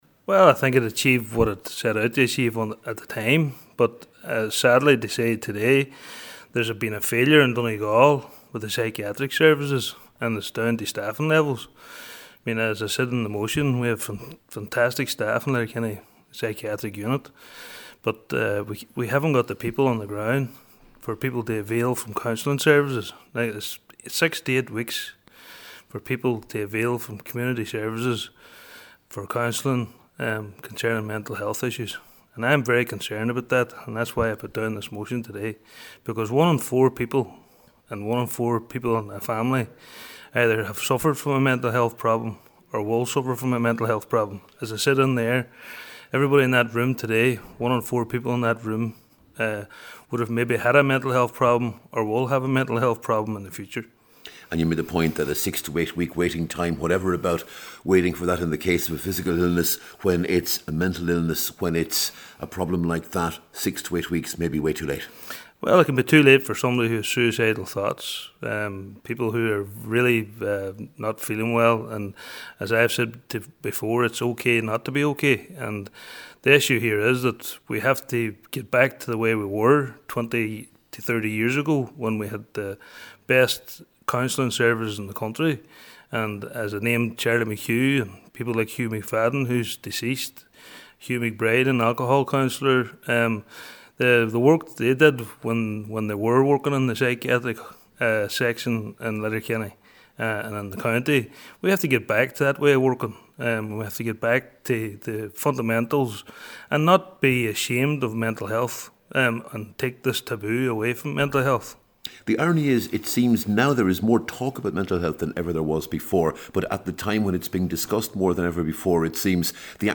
The issue was raised in the chamber by Cllr Frank McBrearty, who told members that Donegal has gone from having the best services in the country to having waiting lists of up to eight weeks.